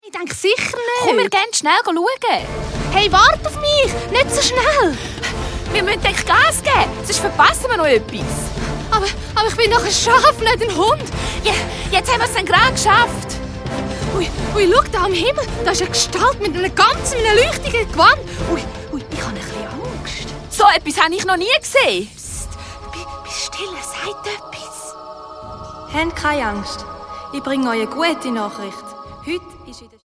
Hörspiel-Album